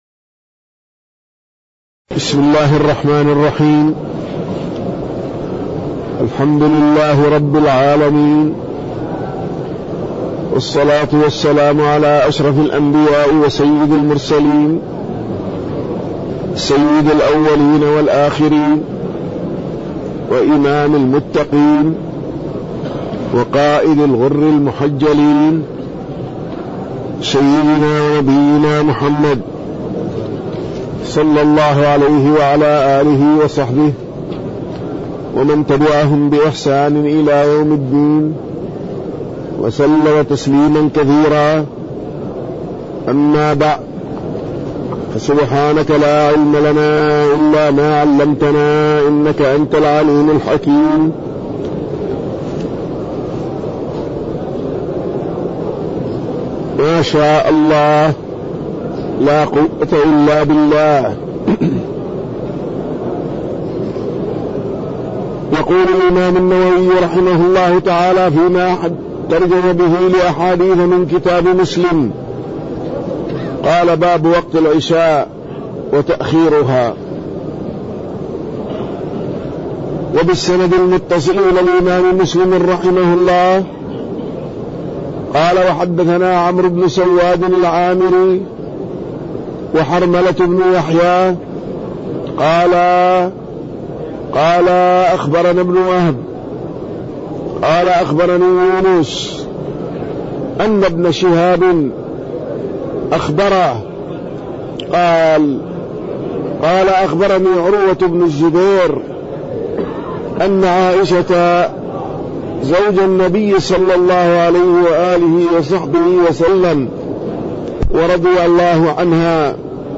تاريخ النشر ١٧ محرم ١٤٣٠ هـ المكان: المسجد النبوي الشيخ